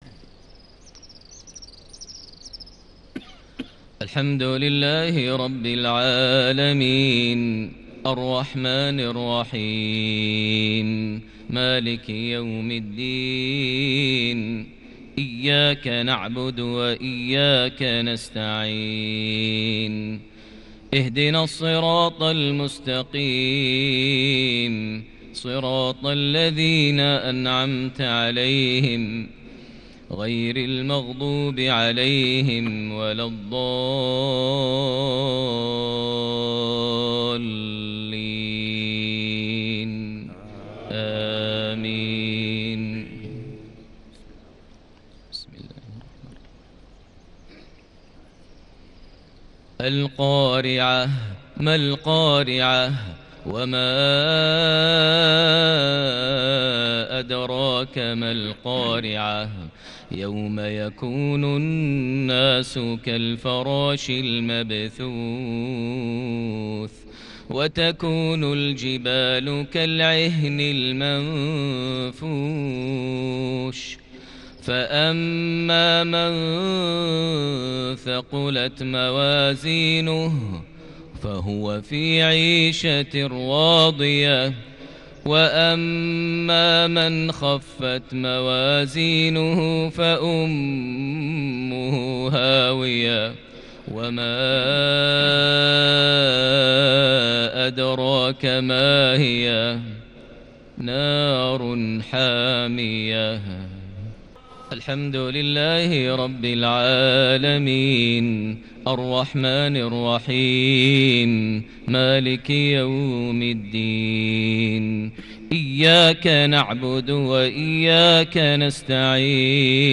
صلاة المغرب ٢٧ جماد الآخر ١٤٤٠هـ سورتي القارعة - التكاثر mghrib 4-3-2019 Surah Al-Qaria + Surah At-Takathur > 1440 🕋 > الفروض - تلاوات الحرمين